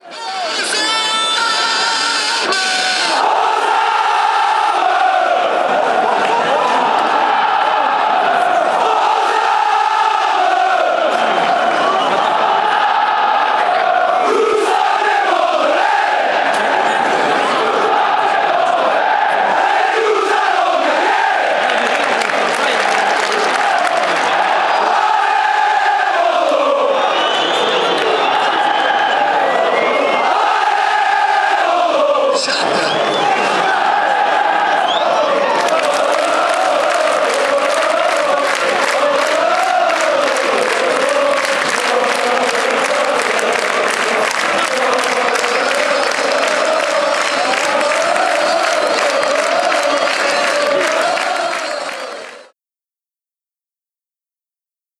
Chants - Saison 09-10
Supporter Virage Sud Centre Haut